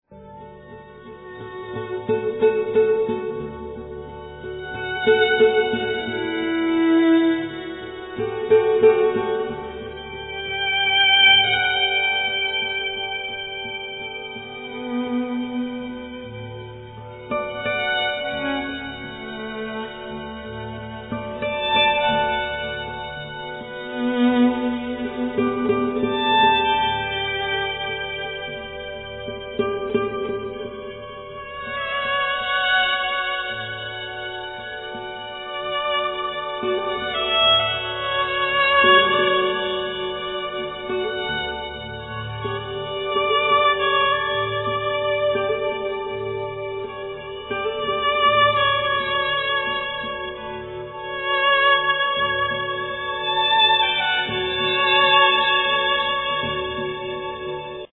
Violin, Viola
Didjeridoo, Zither, Tuned glass
Drums
Guitar